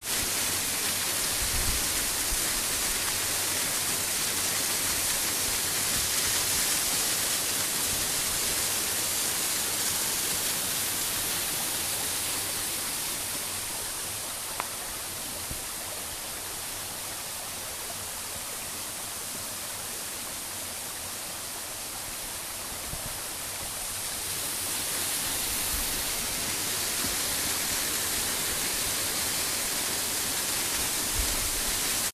Waterfall.ogg